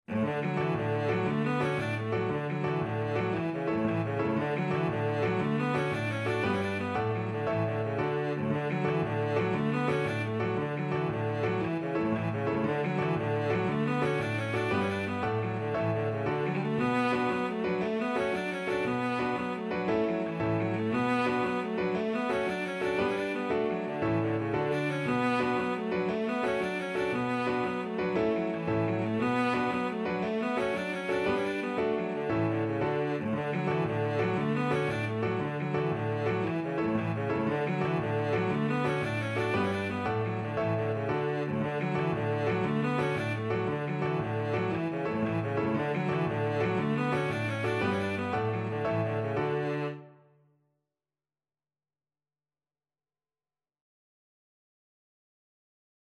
Cello
D major (Sounding Pitch) (View more D major Music for Cello )
With energy .=c.116
Irish